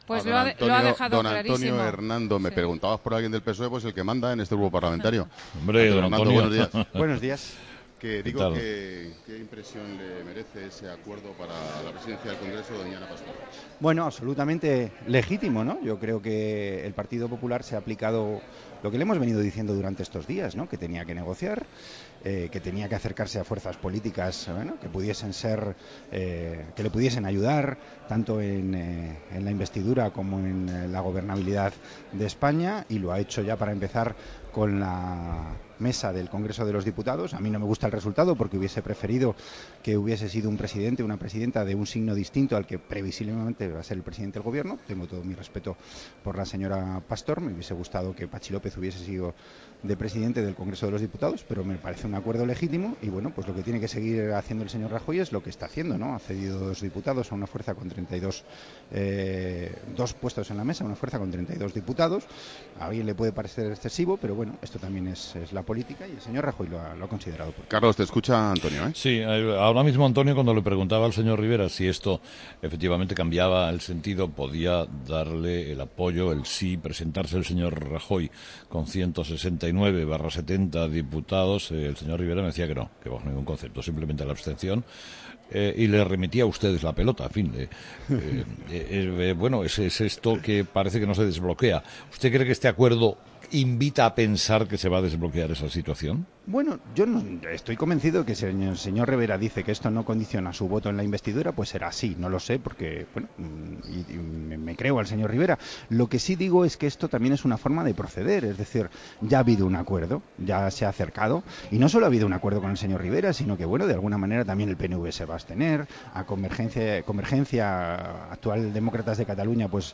Entrevista con Antonio Hernando.
El diputado del PSOE y portavoz en el Congreso, Antonio Hernando, ha dicho en 'Herrera en COPE' que "el PP se ha aplicado a lo que hemos venido diciendo y se ha puesto a negociar"